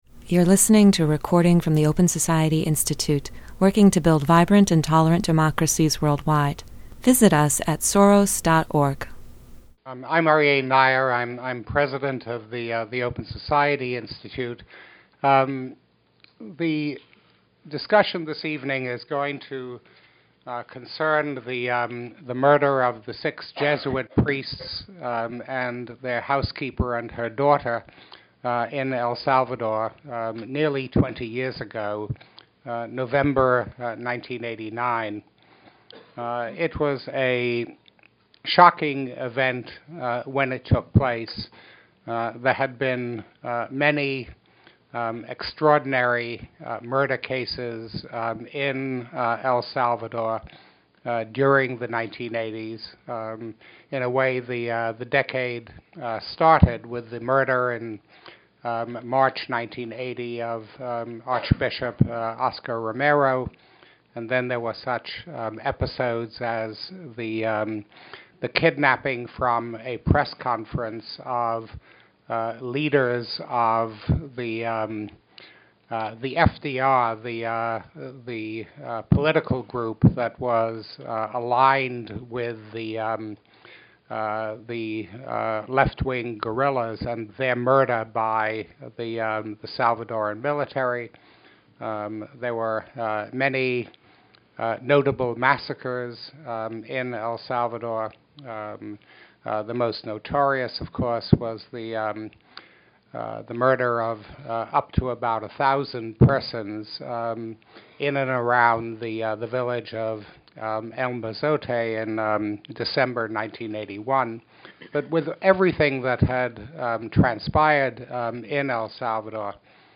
Experts discussed the 1989 murders, their political consequences, and the global struggle to ensure that the perpetrators of great crimes are held accountable.